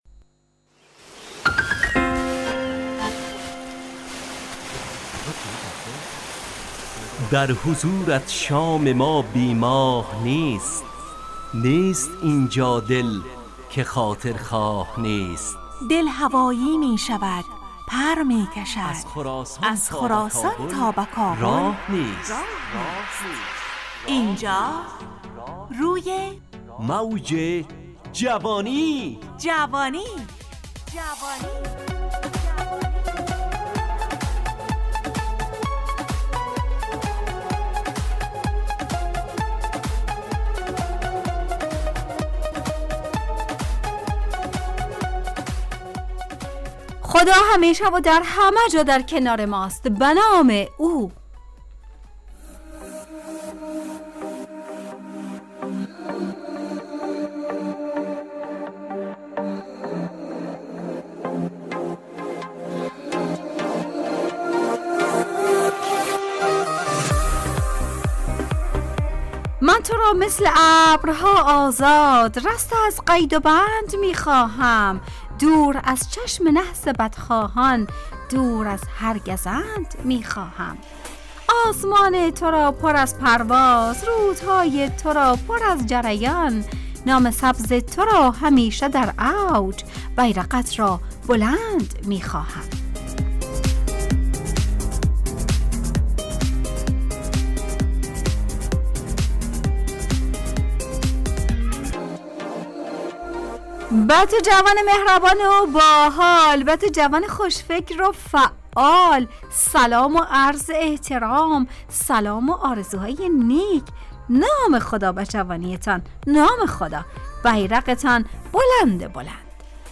روی موج جوانی، برنامه شادو عصرانه رادیودری.